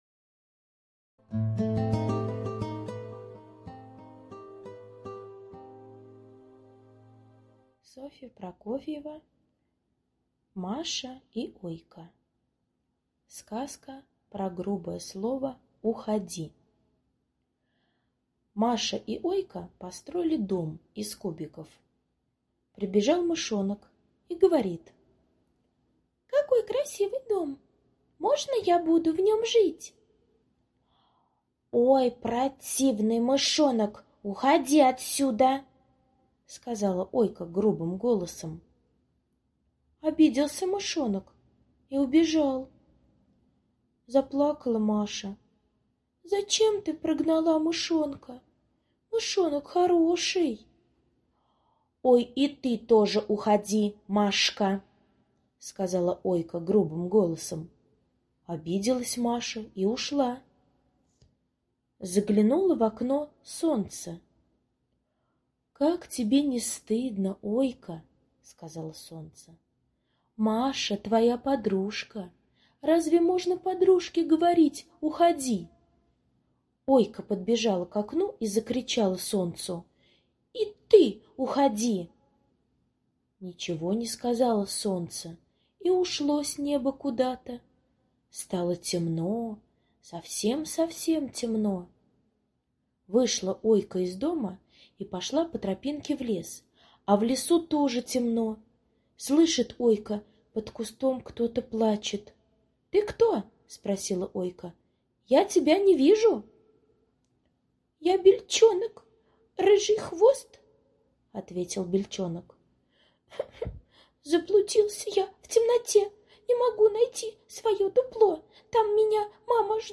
Сказка про грубое слово "уходи" - аудиосказка Прокофьевой С. Сказка про то, как Ойка прогнала от себя Мышонка, Машу и даже Солнце.